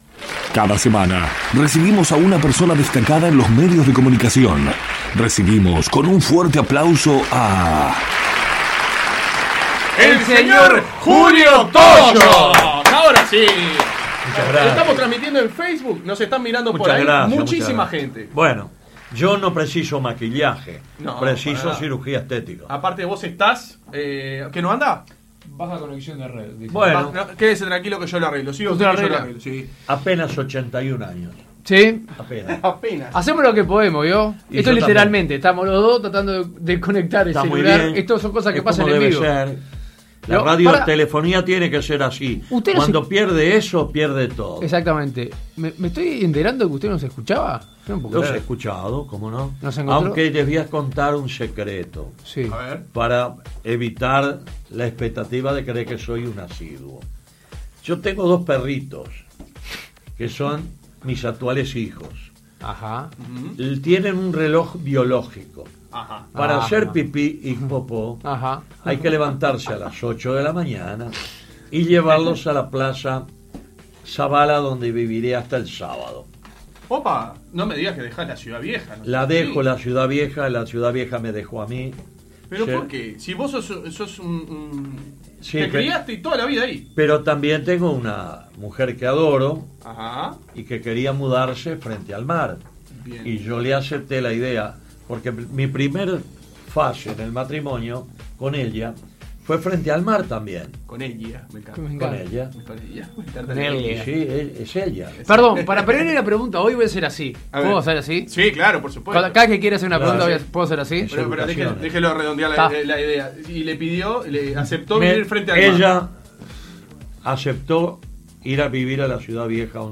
En la entrevista se abordaron varios temas.